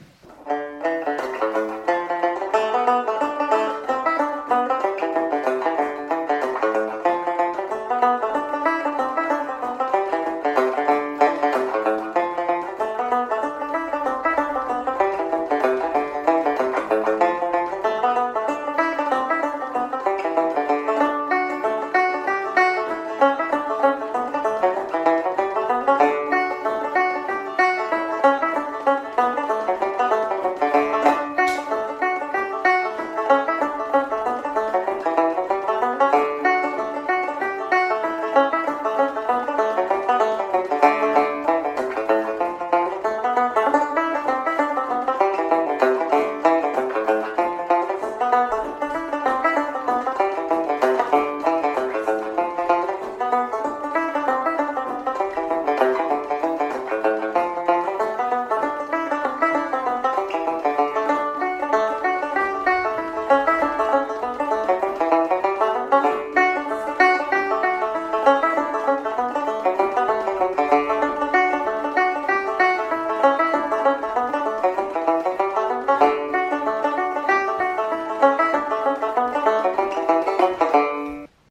Learn Irish trad tunes by ear